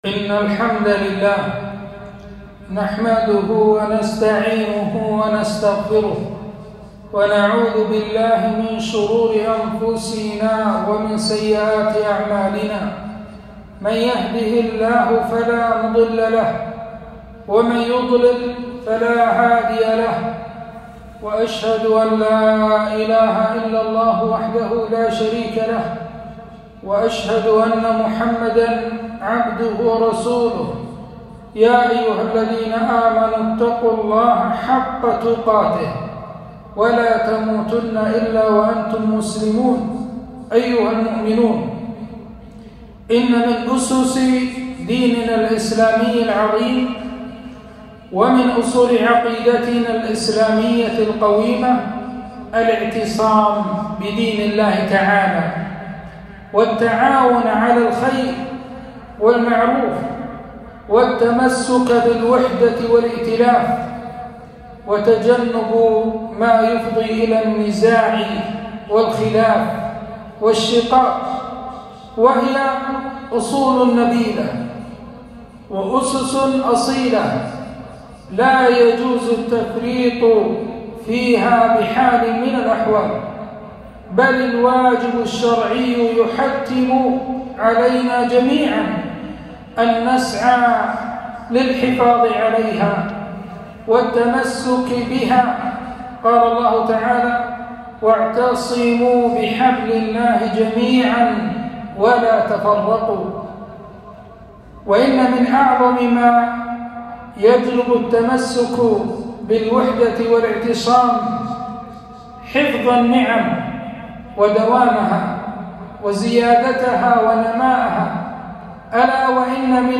خطبة - الكويت أمانة في أعناقنا